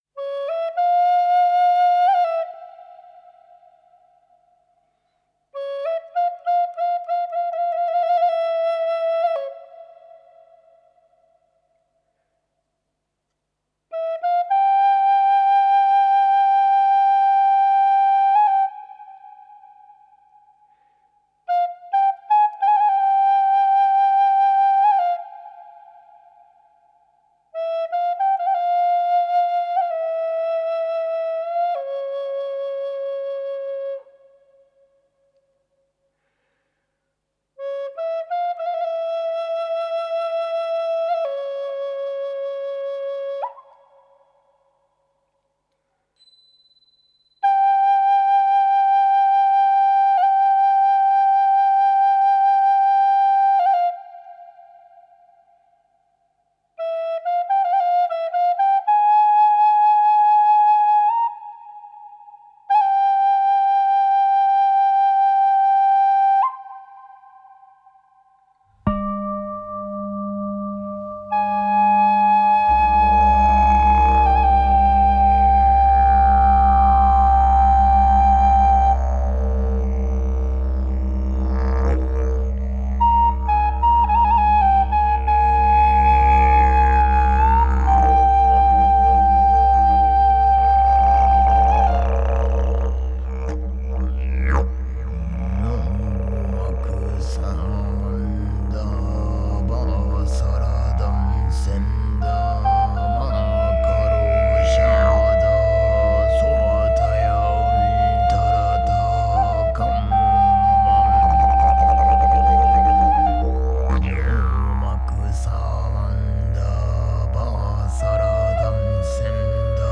For fipple flute, bell, didgeridoo, chant, beatbox